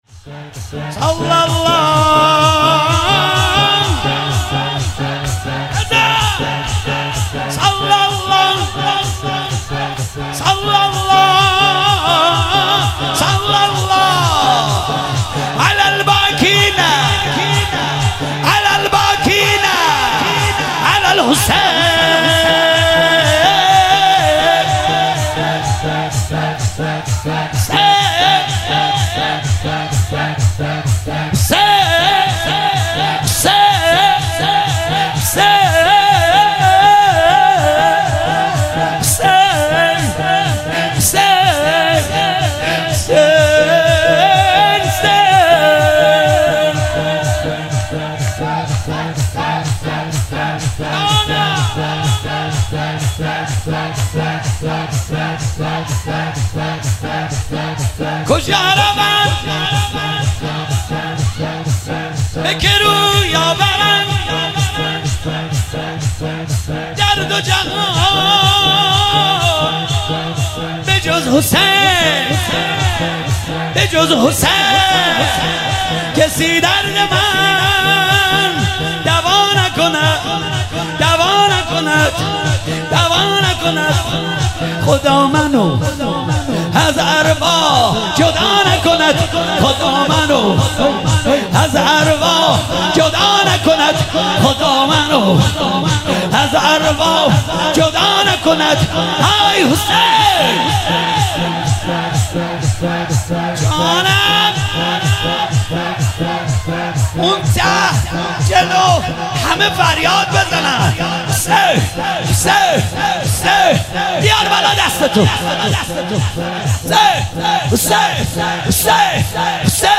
شب هفتم محرم 97 - شور - صلی الله علی الباکین علی الحسین